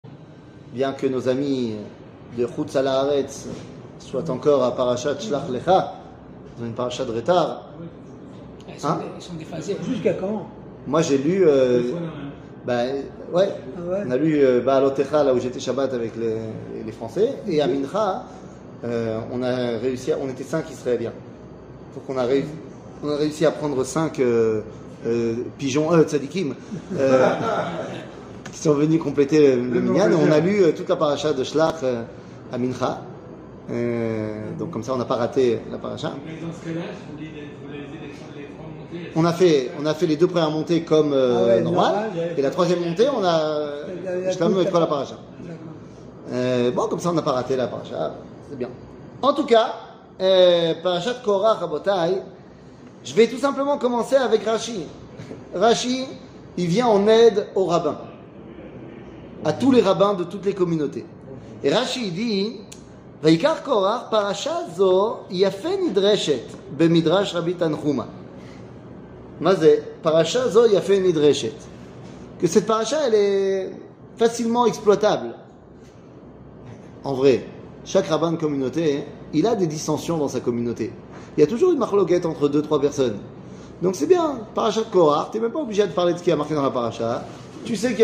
שיעור מ 21 יוני 2022 01MIN הורדה בקובץ אודיו MP3 (1.34 Mo) הורדה בקובץ וידאו MP4 (3.62 Mo) TAGS : שיעורים קצרים